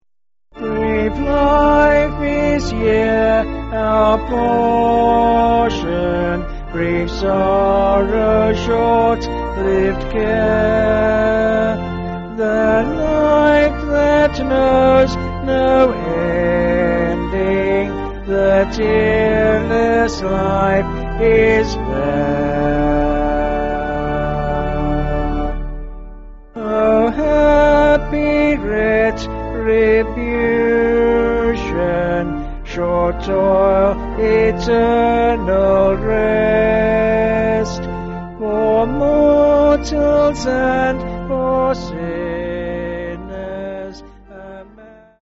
(BH)   9/Dm
Vocals and Organ